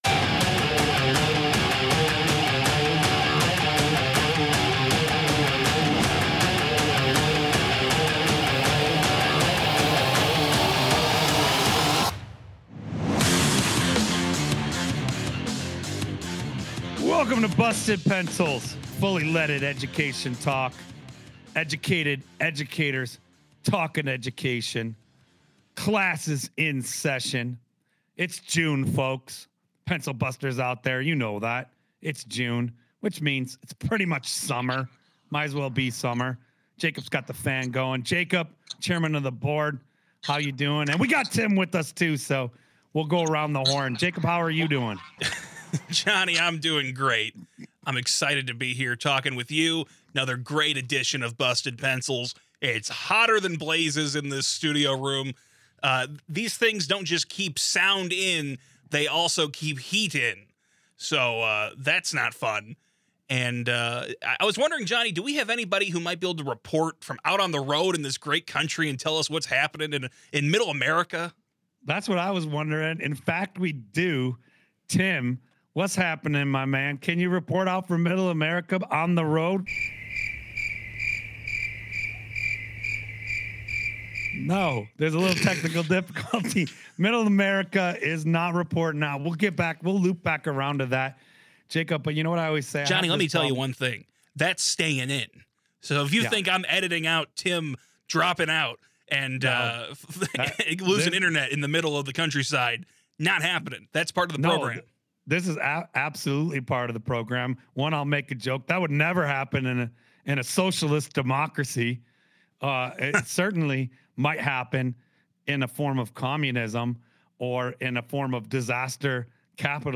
establish a ham radio connection
and a few crickets.